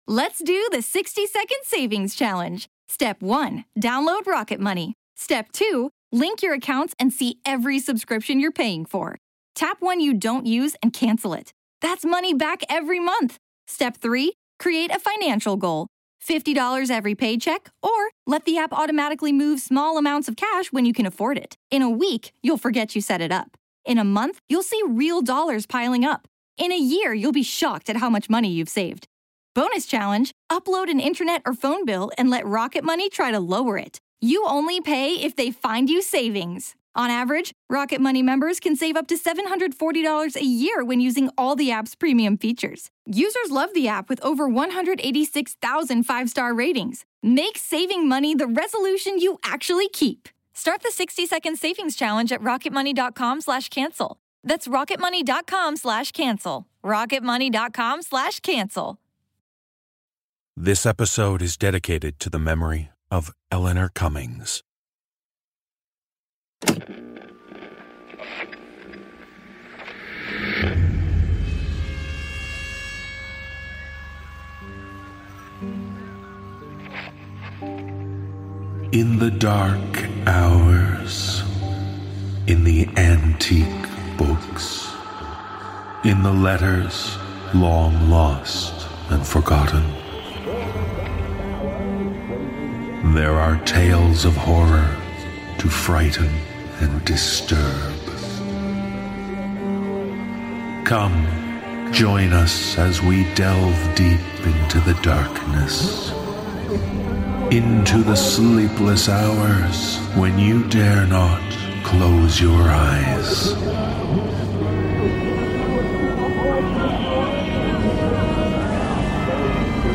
Cast: Narrator